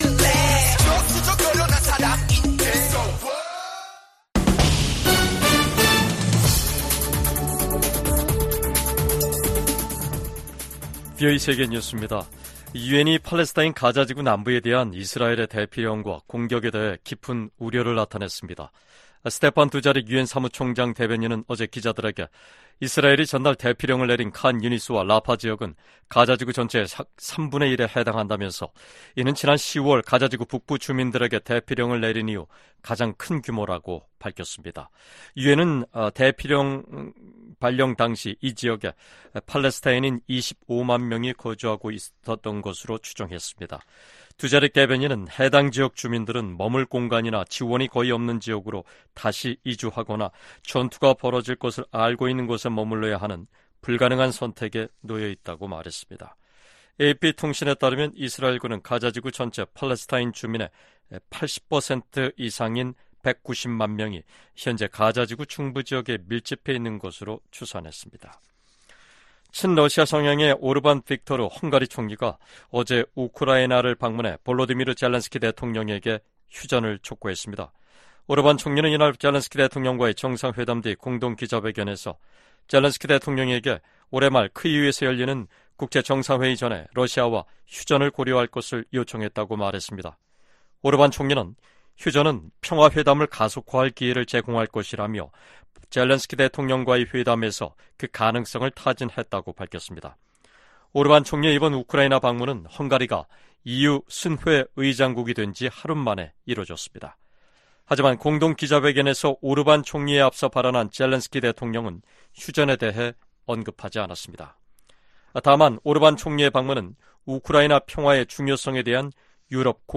VOA 한국어 간판 뉴스 프로그램 '뉴스 투데이', 2024년 7월 3일 3부 방송입니다. 미국 국방부가 북한의 최근 탄도미사일 발사를 비판하며 계속 심각하게 받아들일 것이라고 밝혔습니다. 미국의 미사일 전문가들은 북한이 아직 초대형 탄두 미사일이나 다탄두 미사일 역량을 보유하지 못한 것으로 진단했습니다. 유엔 제재하에 있는 북한 선박이 중국 항구에 입항했습니다.